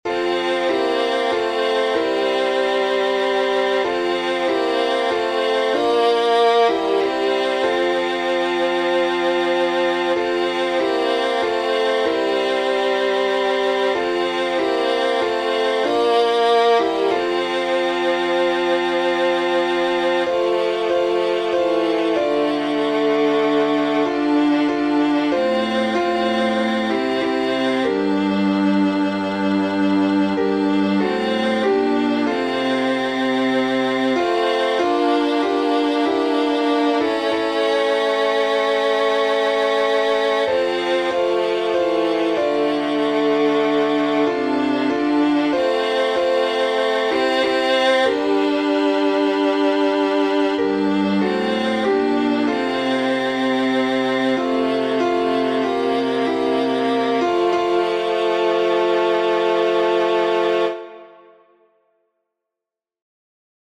Be Still My Soul — F Major with 5 stanzas.
Key signature: F major (1 flat) Time signature: 4/4